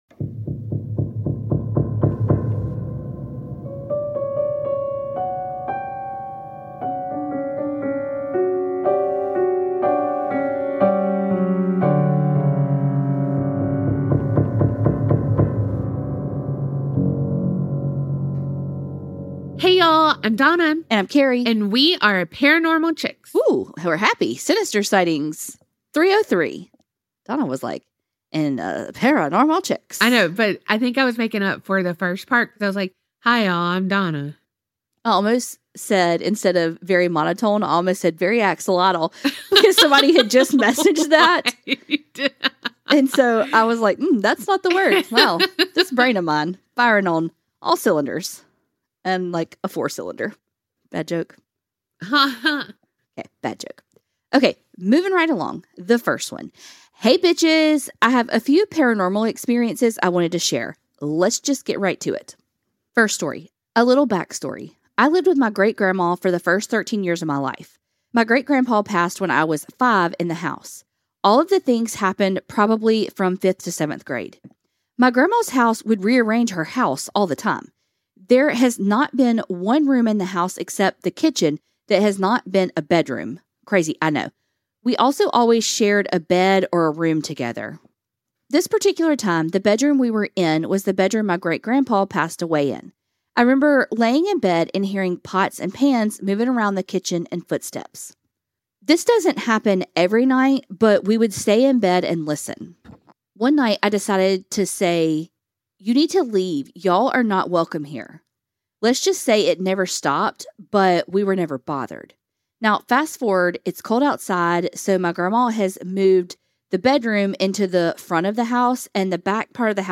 Sinister Sightings are your true crime and true paranormal stories. Every week we read out ones that you've sent in.